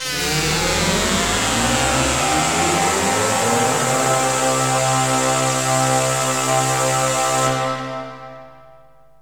SYNTH GENERAL-1 0004.wav